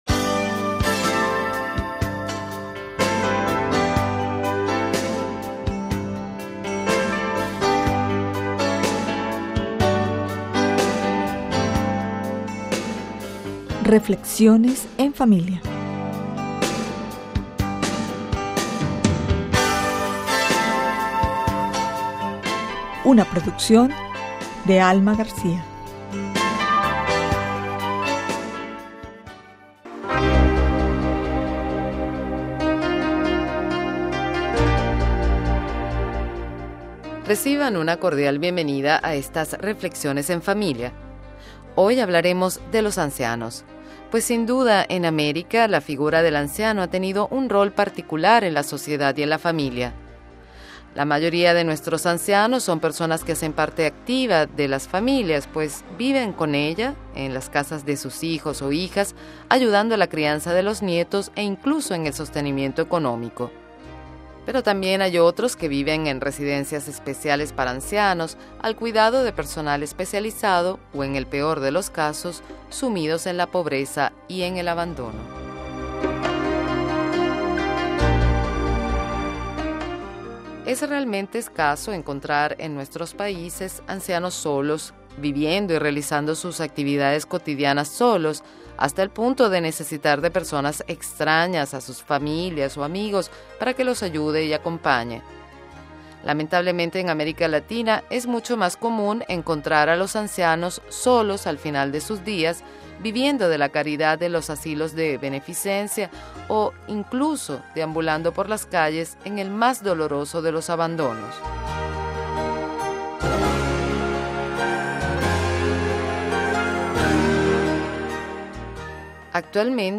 Locución: